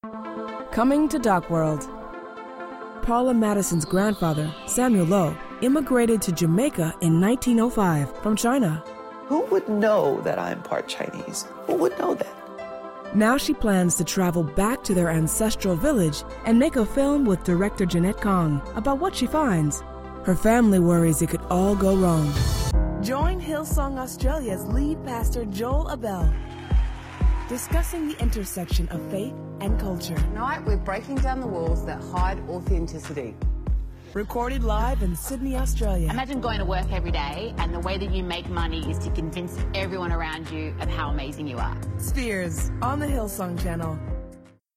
Demo
promos
cool
friendly
smooth
trustworthy
warm